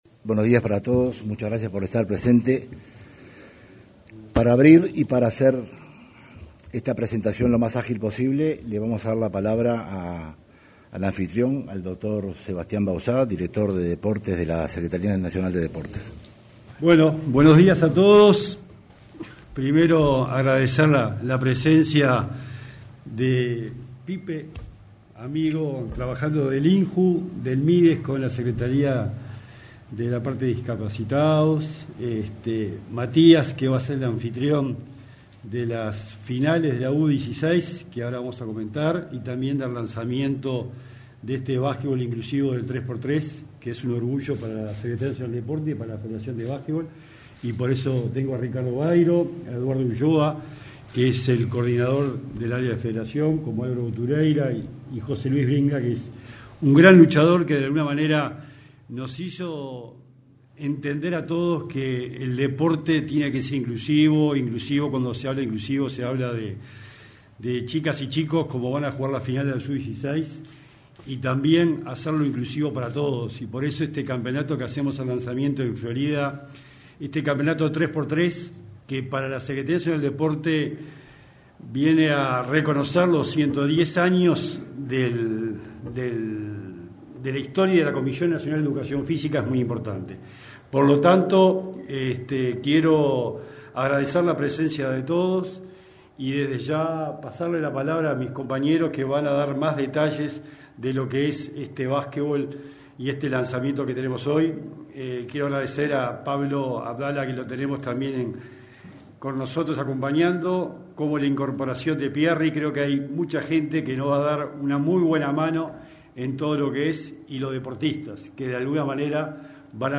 El titular de la Secretaría Nacional del Deporte (SND), Sebastián Bauzá, encabezó el lanzamiento de las finales del torneo nacional de básquetbol 3x3,